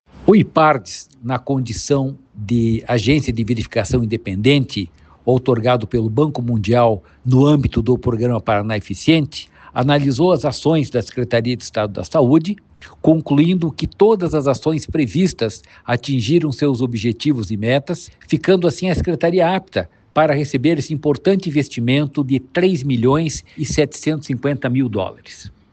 Sonora do presidente do Ipardes, Jorge Callado, sobre o repasse do Banco Mundial para a Secretaria da Saúde